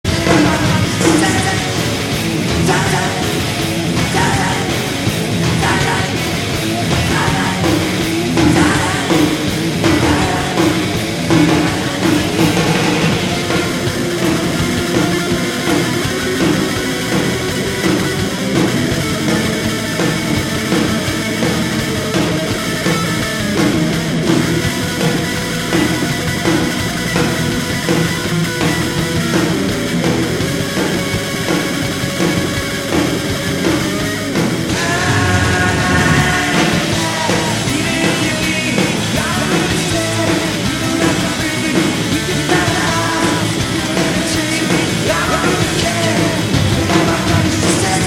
The Palace (Rock For Choice Benefit), Hollywood, CA, US